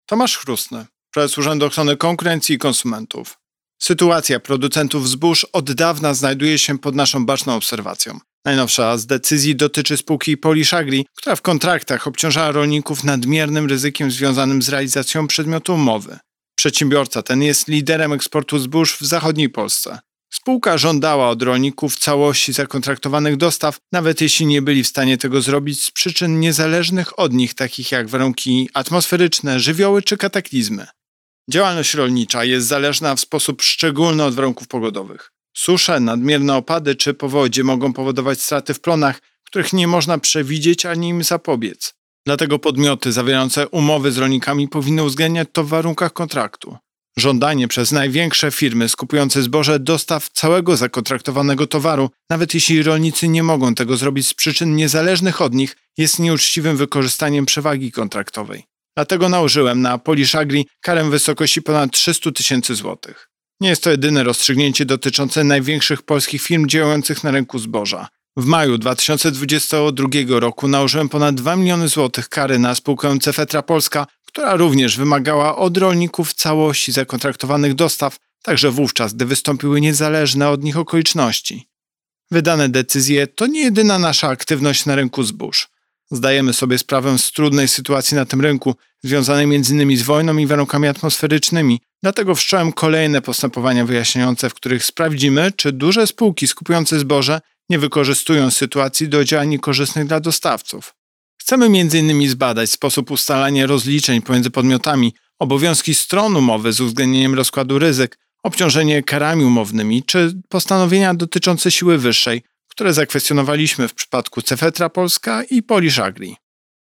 Wypowiedź Prezesa UOKiK Tomasza Chróstnego z 4 sierpnia 2022 r..mp3